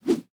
Weapon Sword Whips 01.wav